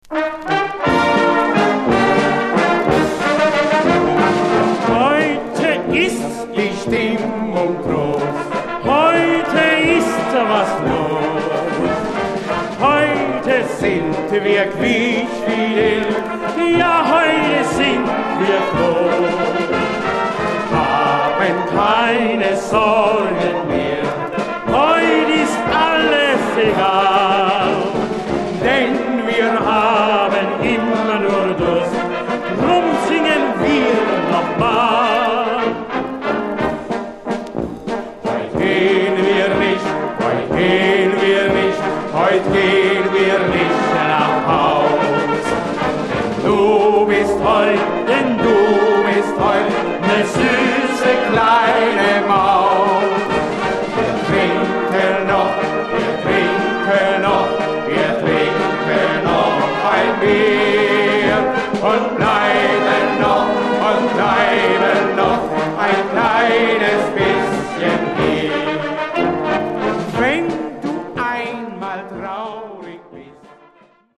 Schallplattenaufnahme